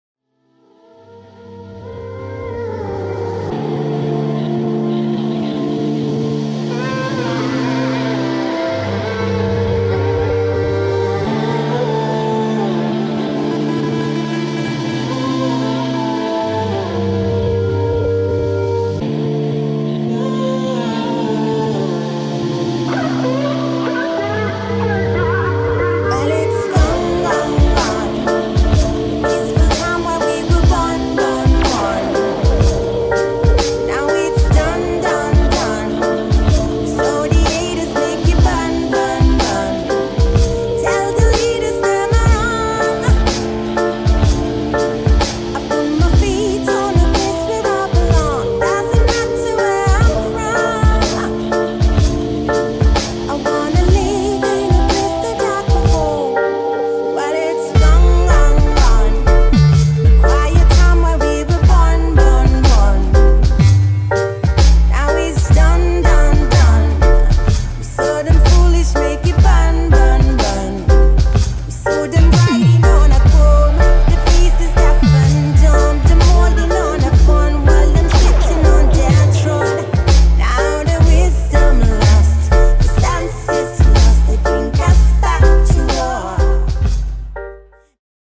[ REGGAE / DUB / DUBSTEP ]